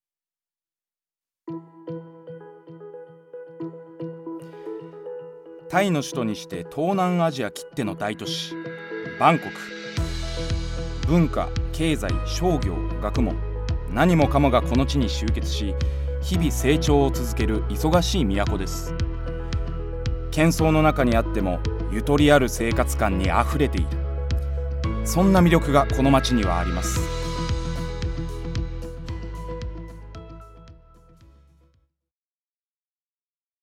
ナレーション
ボイスサンプル